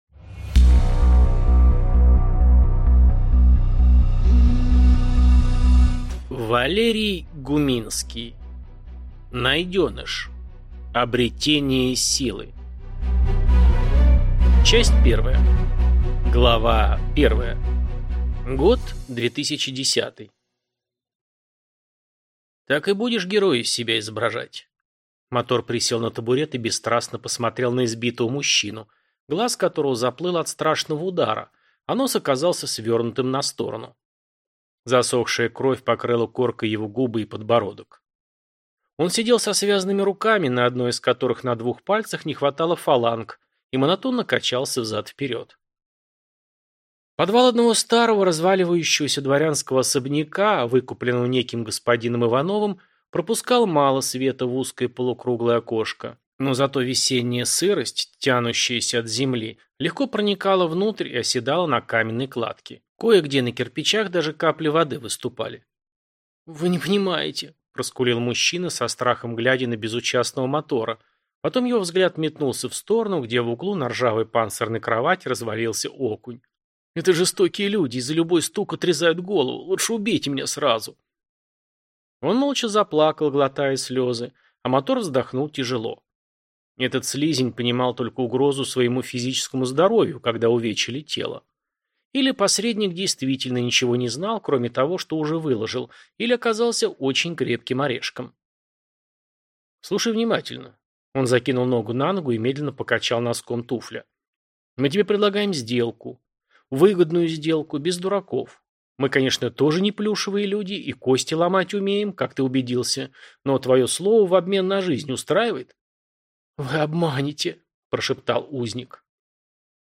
Аудиокнига Найденыш. Обретение Силы | Библиотека аудиокниг